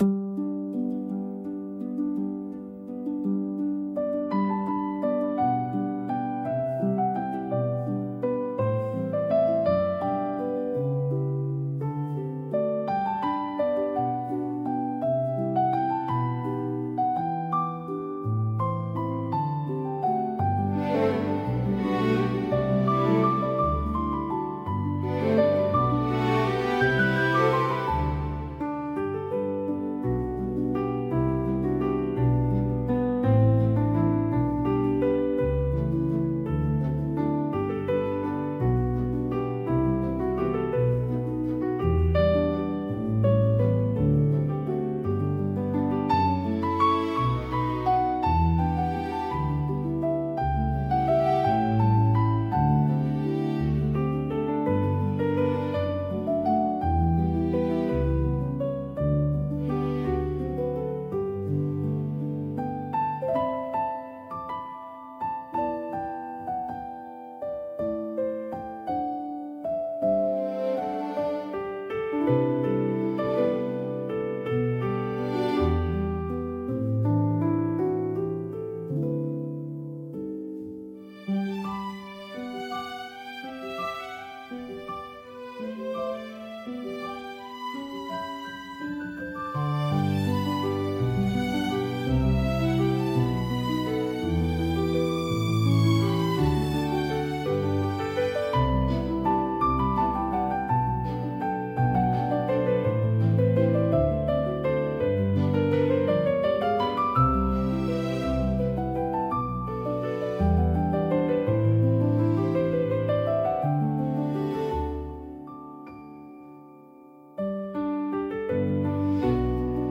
明るさと元気さで、場の雰囲気を一気に盛り上げ、聴く人にポジティブな感情を喚起します。